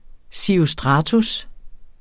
Udtale [ siʁoˈsdʁɑːtus ]